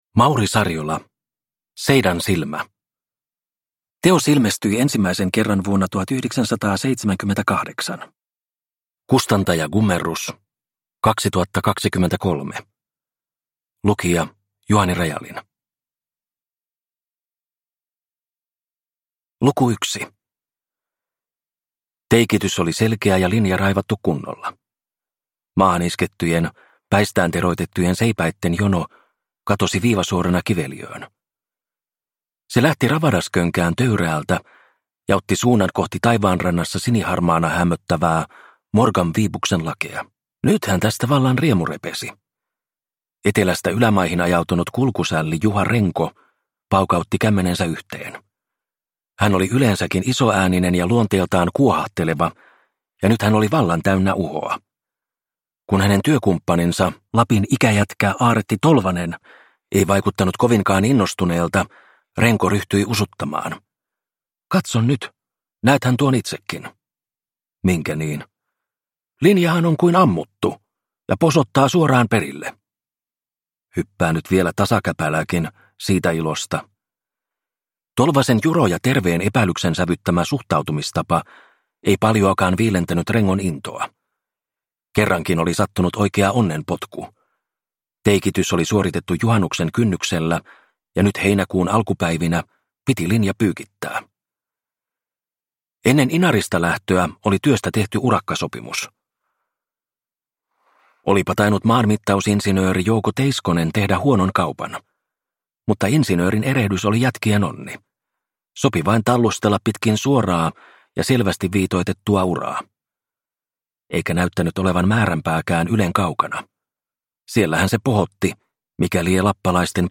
Seidan silmä – Ljudbok – Laddas ner